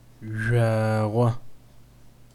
ʒʷ audio speaker icon
жъуагъо French joie